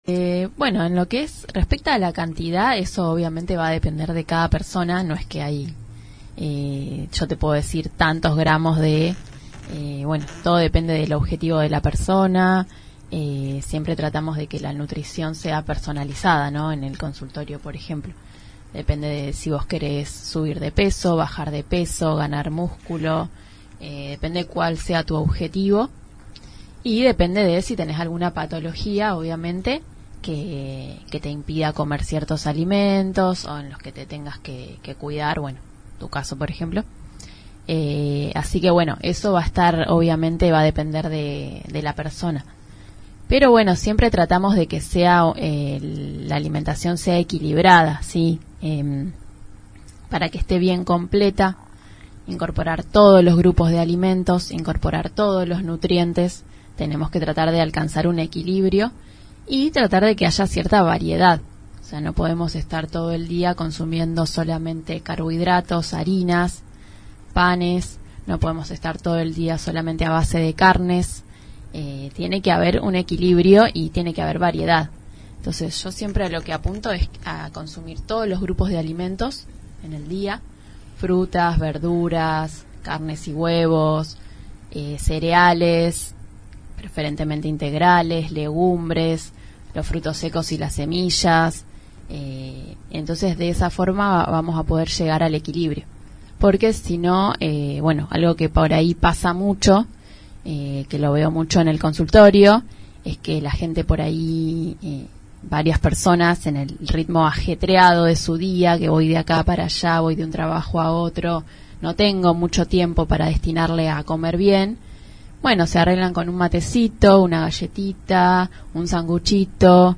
La joven licenciada en nutrición visitó los estudios de la 91.5 para hablar de alimentación saludable.